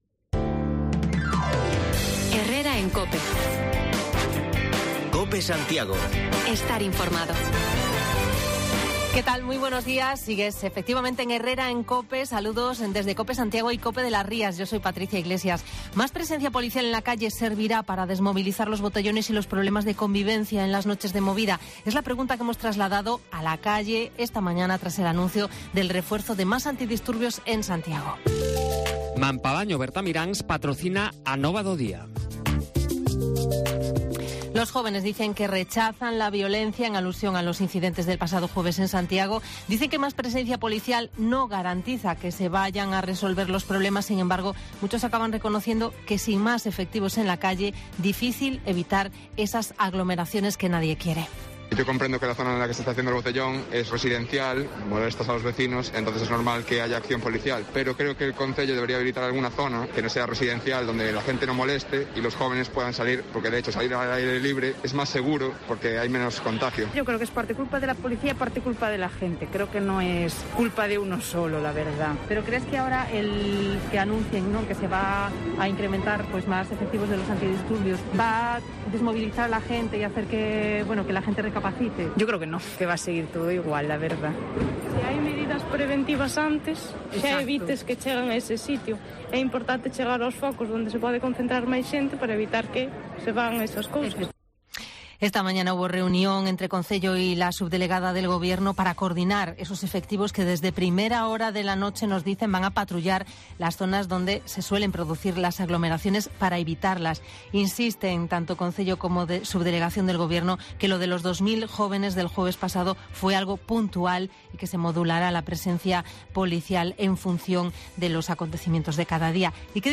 Actualizamos lo más destacado en esta jornada, con voces de jóvenes y vecinos sobre las medidas para atajar el botellón.